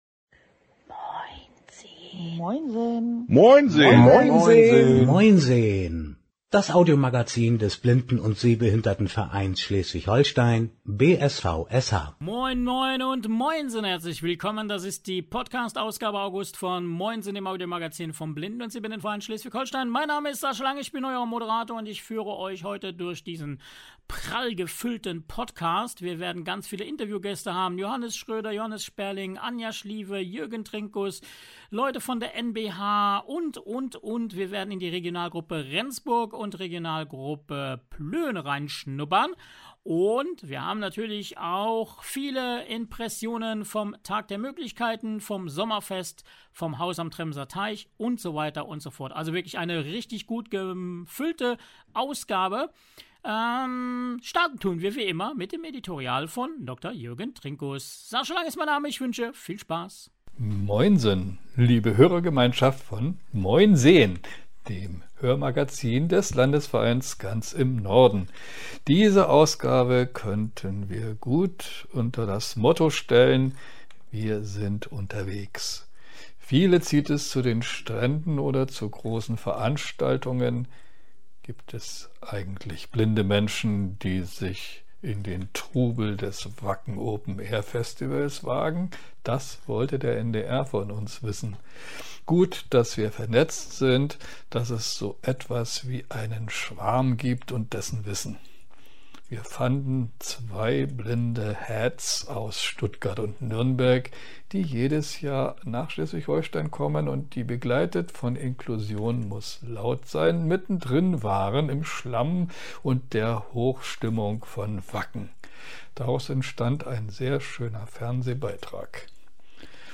In dieser Folge erwartet euch wieder eine bunte Mischung aus Informationen, Interviews und Eindrücken rund um den Blinden- und Sehbehindertenverein Schleswig-Holstein e.V.